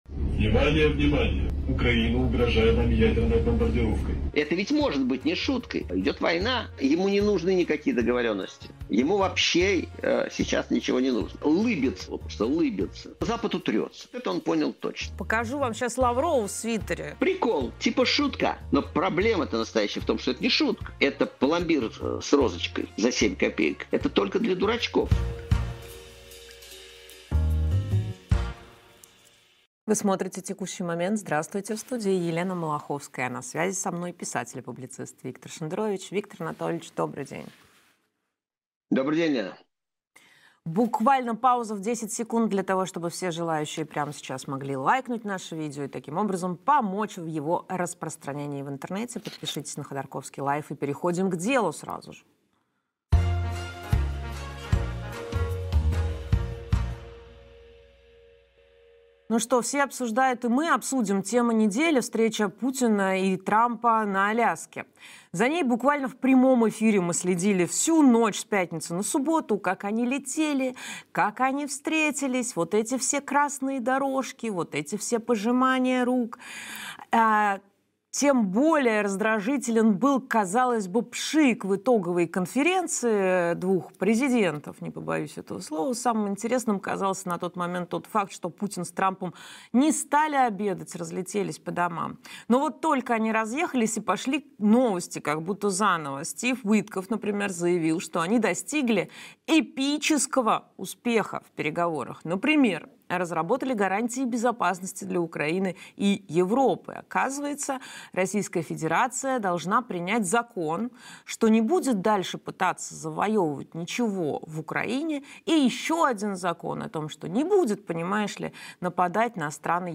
А на связи со мной писатель и публицист Виктор Шендерович.